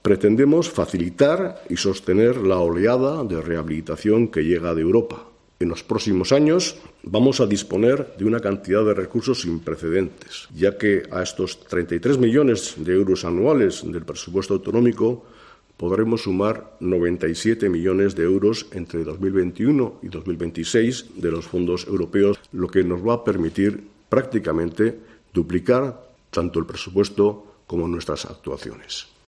Iñaki Arriola, consejero de Planificación Territorial, Vivienda y Transportes